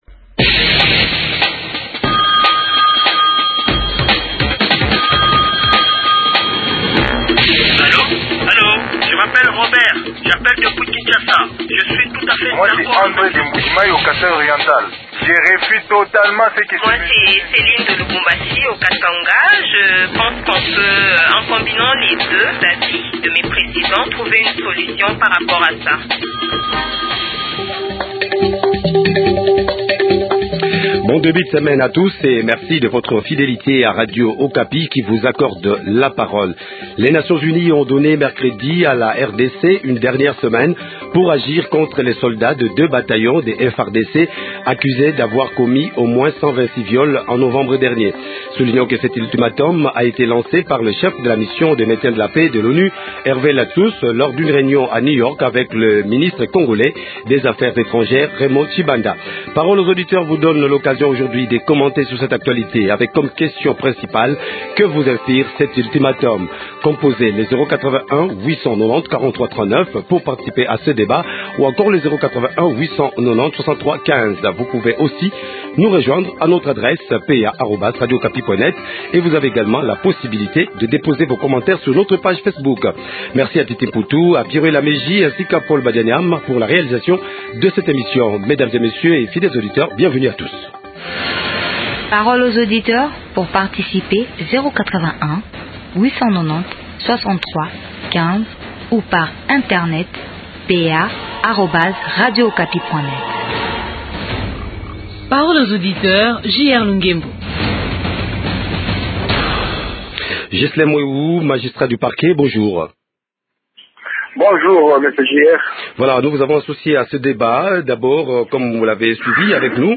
Les auditeurs de Radio Okapi commentent cette actualité avec comme question.